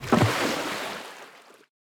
splash_big.ogg